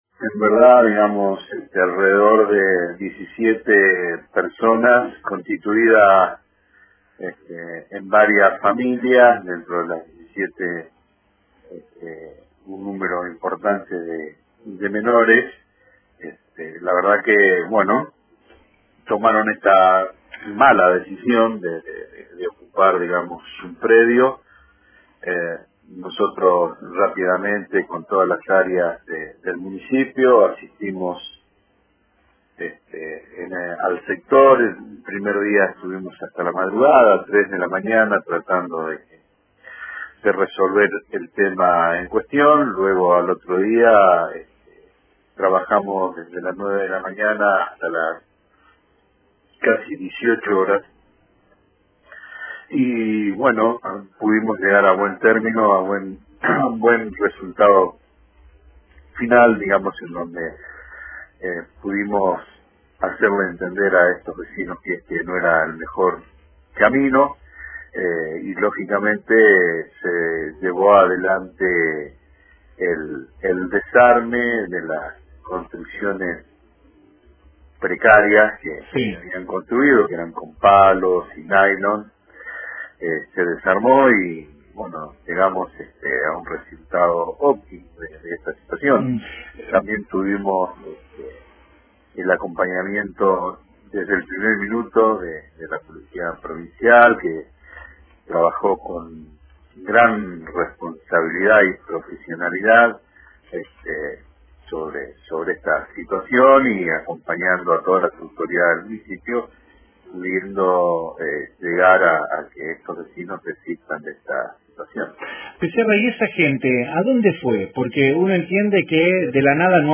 Tras la fallida empresa de un numeroso grupo de familias, el Secretario de Gobierno de la capital dialogó con Radio Fueguina y reconoció que la problemática habitacional y la crisis en general motivó la usurpación.